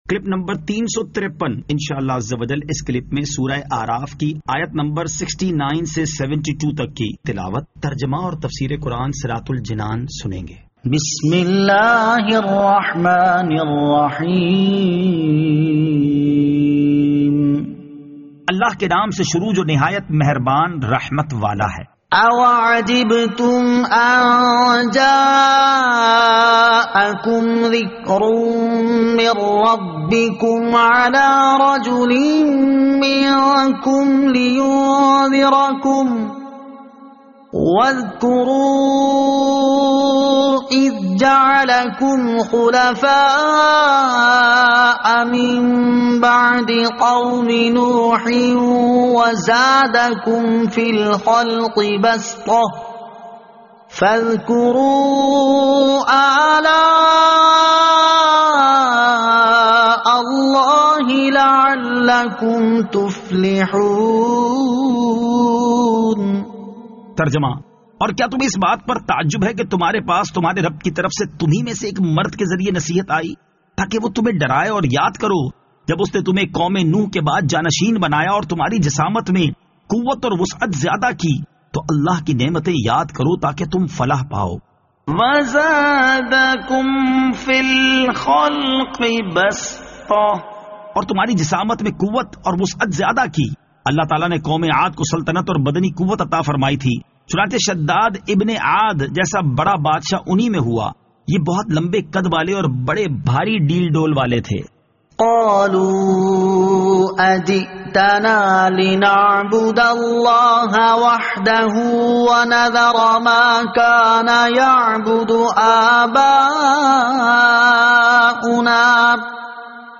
Surah Al-A'raf Ayat 69 To 72 Tilawat , Tarjama , Tafseer